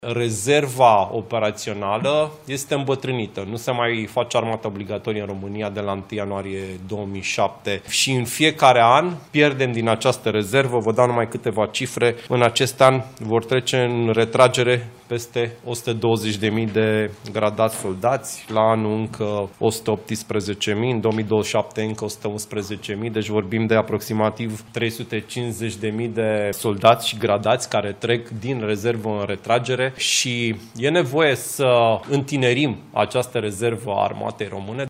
Ministrul Apărării, Ionuț Moșteanu: E nevoie să întinerim rezerva Armatei Române